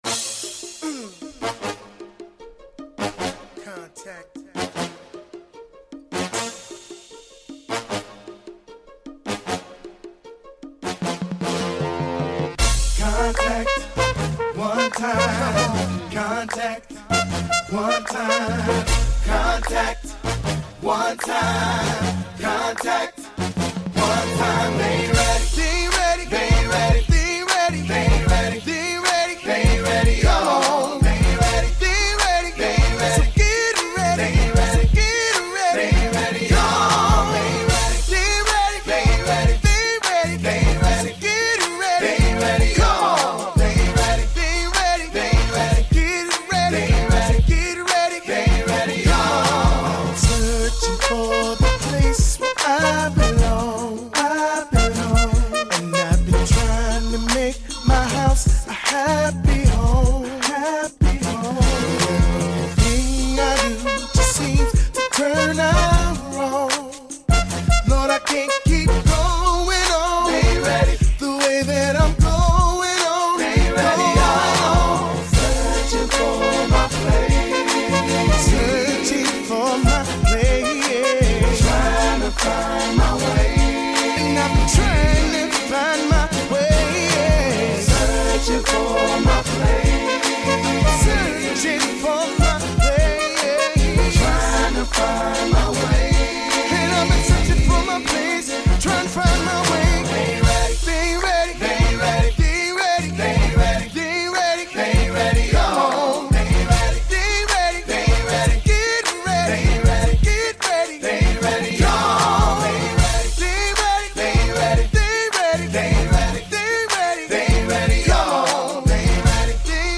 They rocked there hood with beautiful harmomy.